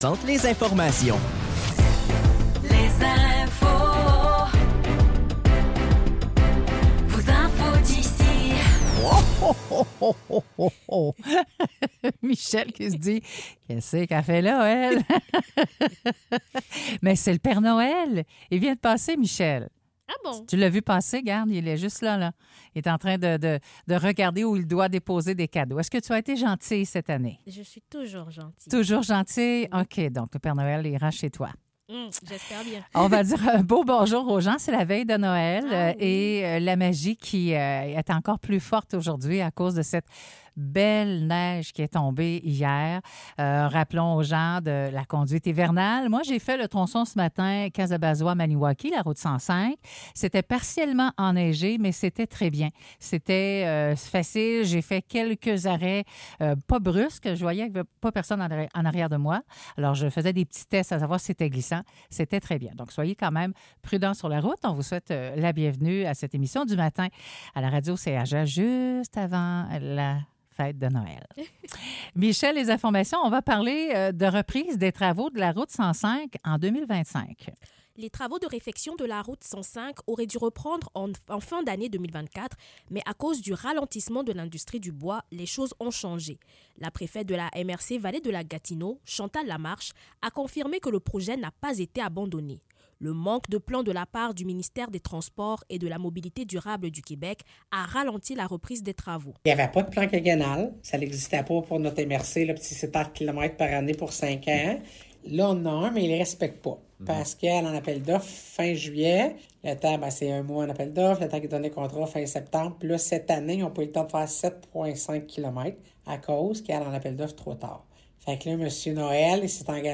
Nouvelles locales - 24 décembre 2024 - 7 h